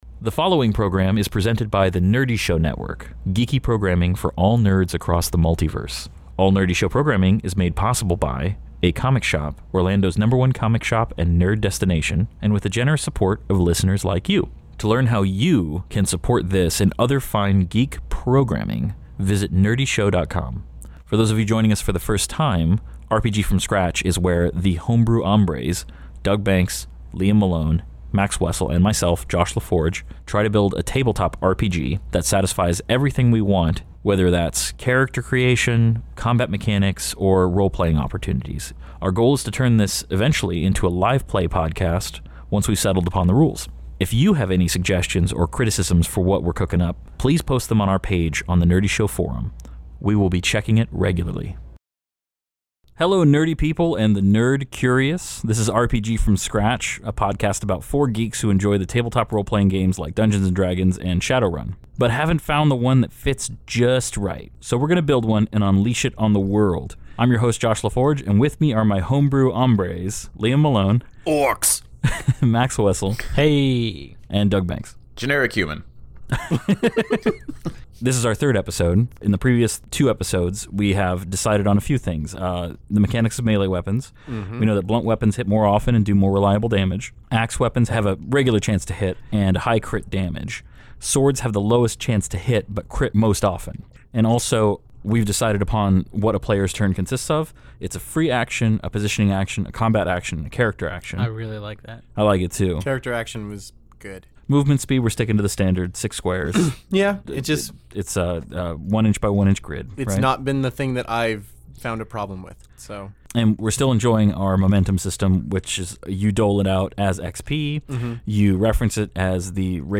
Listen in as as four homebrew hombres design, test, and rebalance their own RPG From Scratch. In this episode, we delve into some light worldbuilding to establish which races will be playable in our fantasy world, how their attributes differ, what kinds of powers they would have, and how they fit into our overarching world.